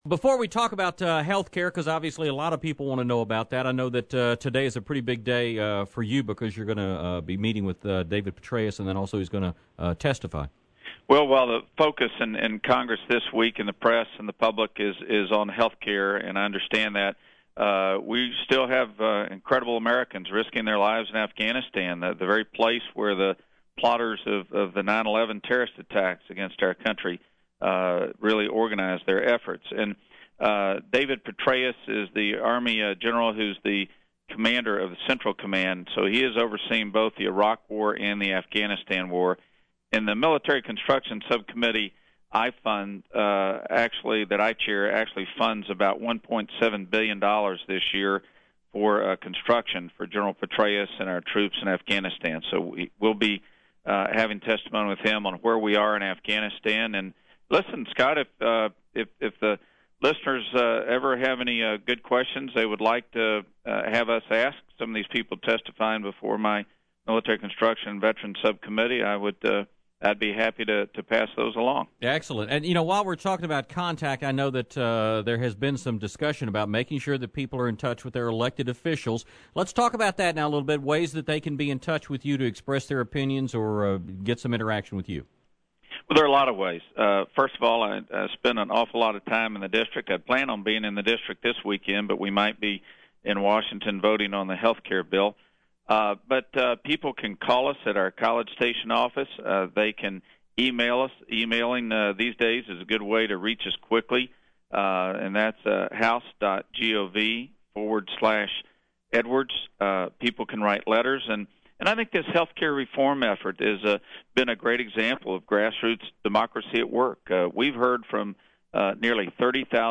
Chet Edwards Interview – March 17, 2010